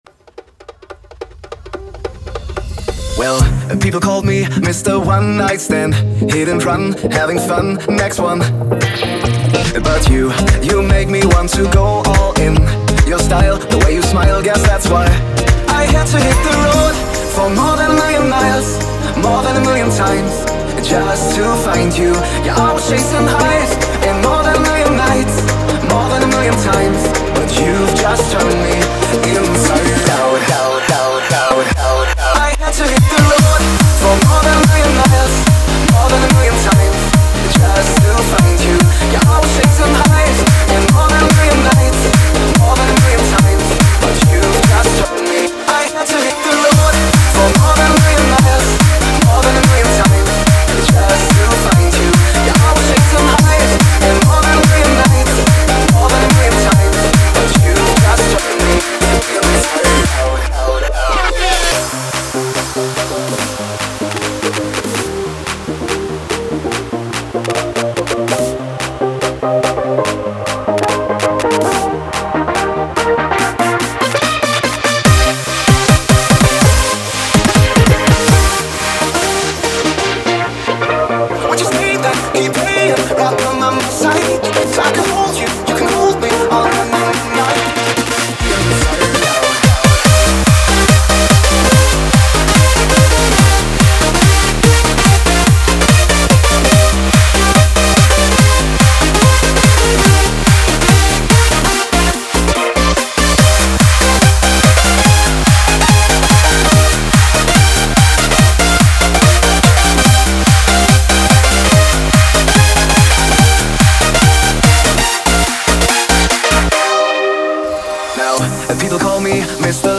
a Hands Up song